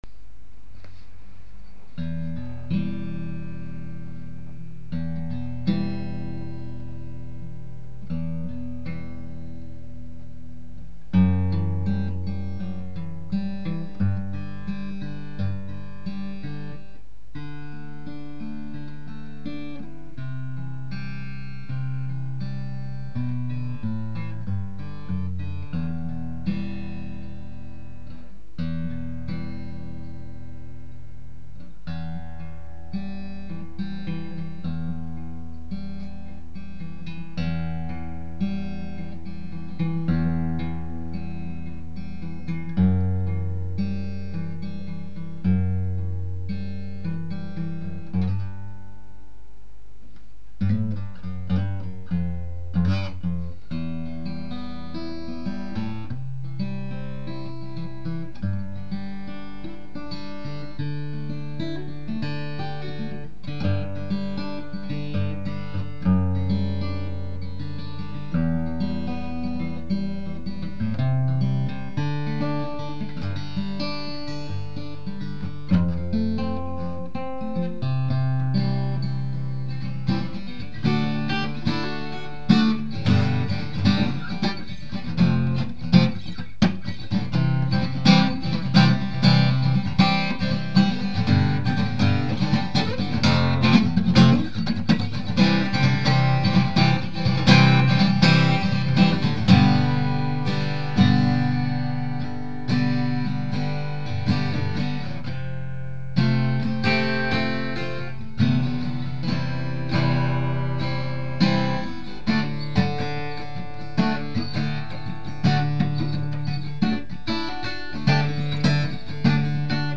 Petite impro sur ma Taylor 414 CE avec Micro pourri
Elle a vraiment un super son ta guitare...
Par contre, il va falloir qu'on fasse un achat groupé pour des micros de qualité...
Impro2.mp3